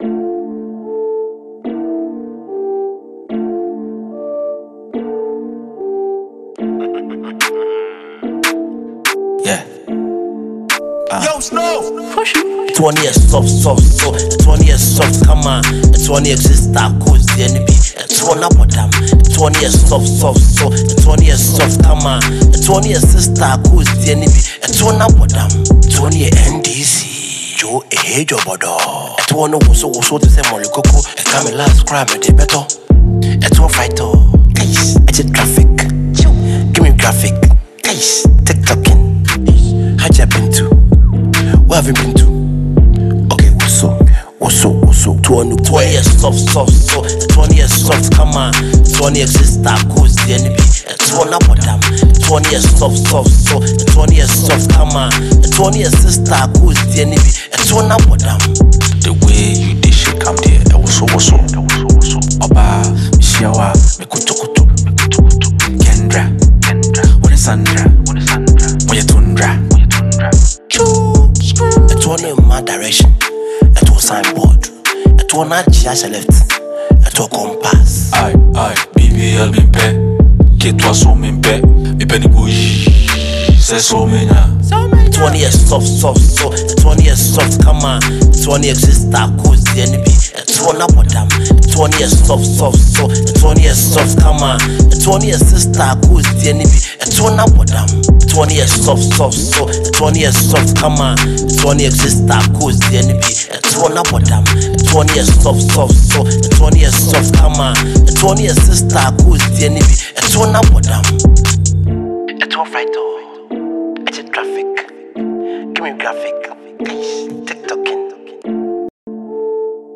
a jam tune